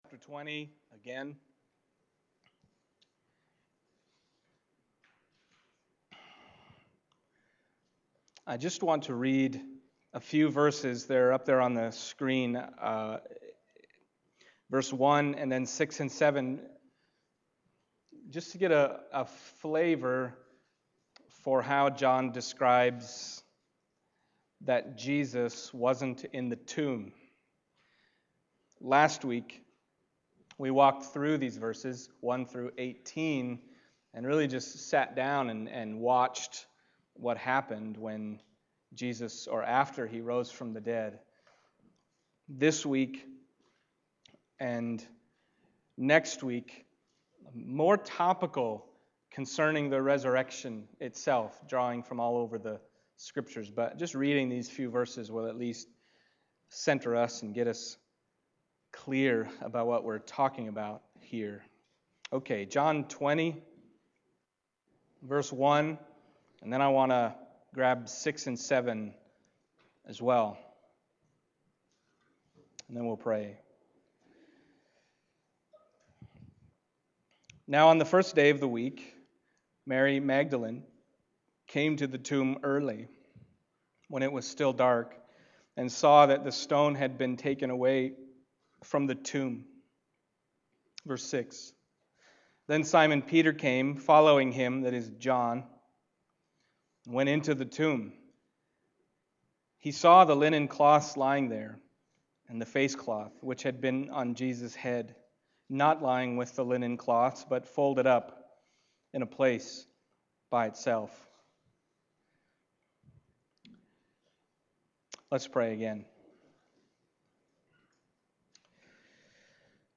John 20:6-7 Service Type: Sunday Morning John 20:1